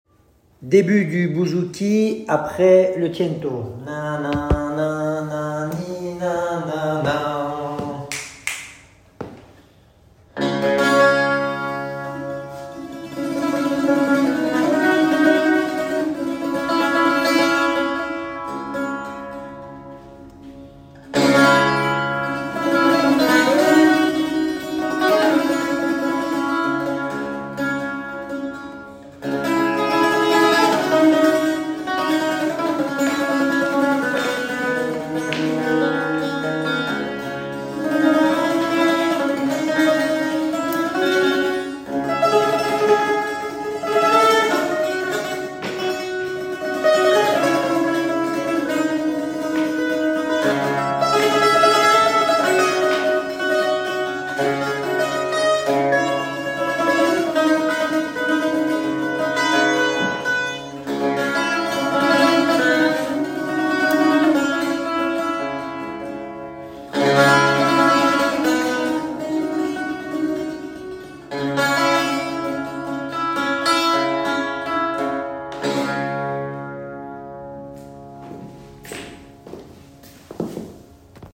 REPETITIONS EL GRAN CIRCO
1_14 – entrée bouzouki rapide (audio):